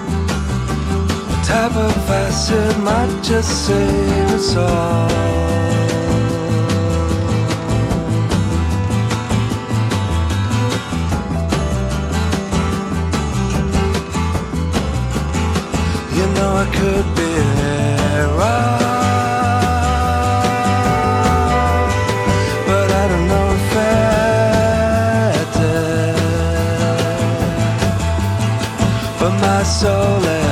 enregistré et mixé en Suède